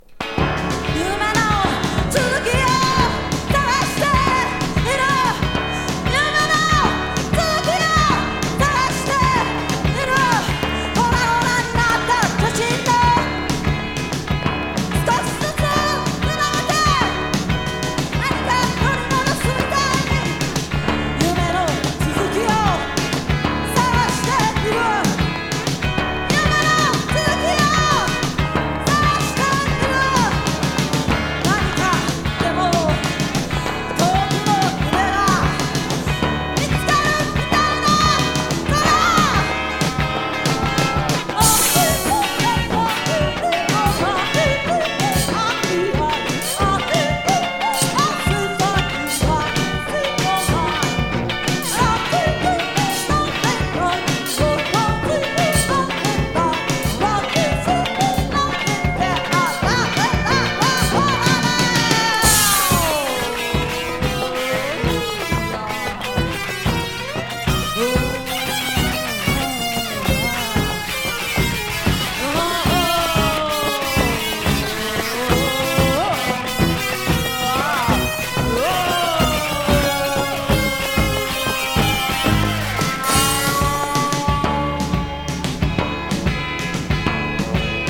カナダのケベック州ビクトリアビルで開催された第4回国際音楽祭で録音されたライブアルバム